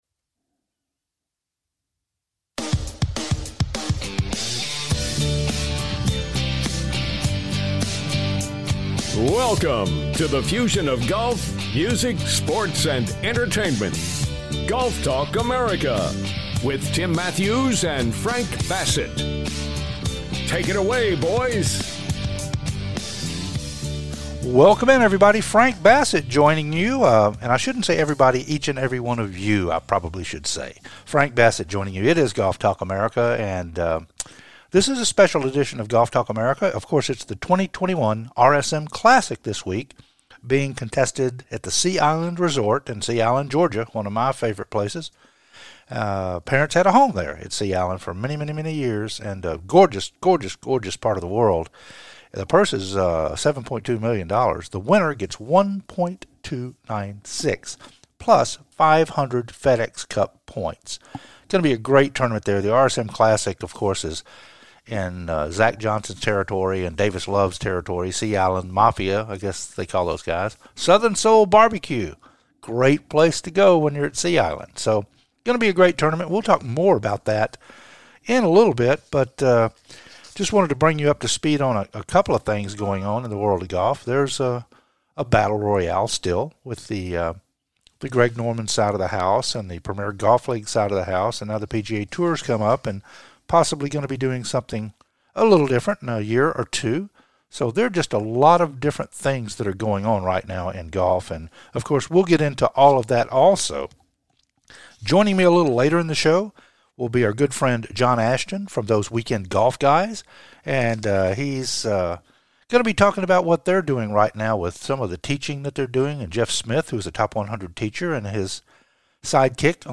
"LIVE" FROM THE IBEROSTAR GRAND ROSE HALL, MONTEGO BAY